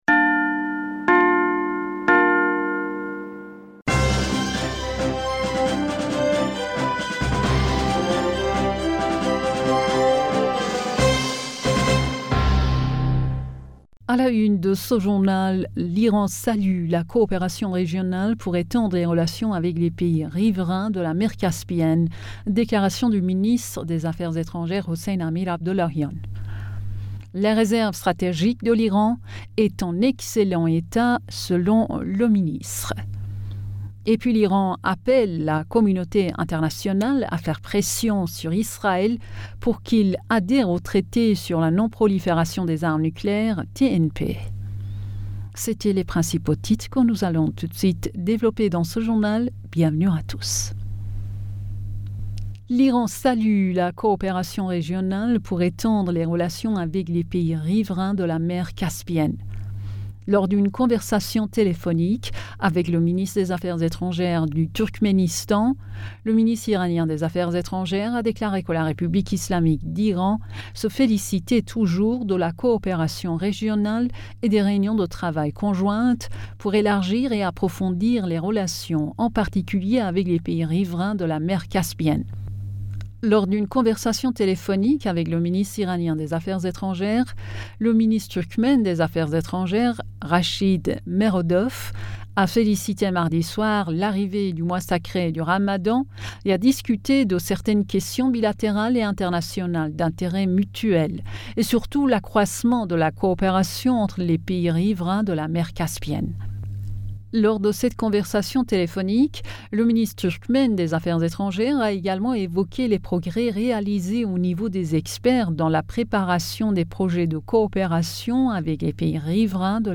Bulletin d'information Du 06 Avril 2022